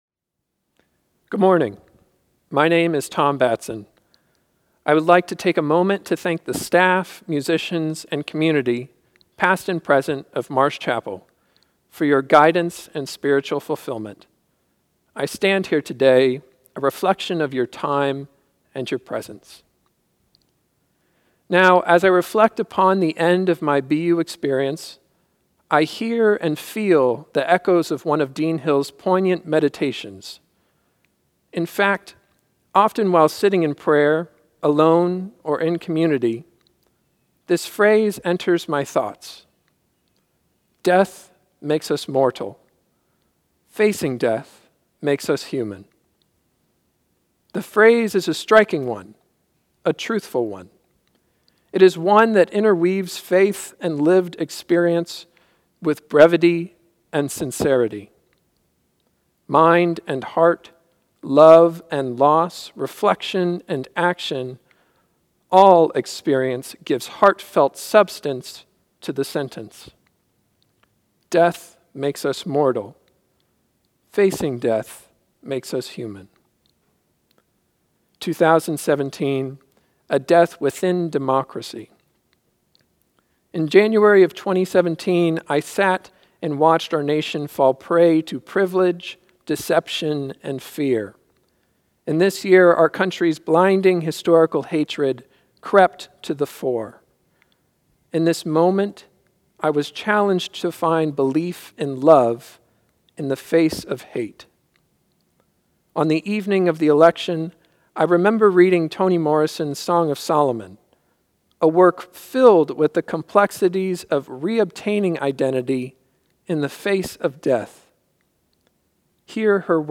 He recorded his reflection from his home church, Foothills United Methodist Church, in San Diego, Calif.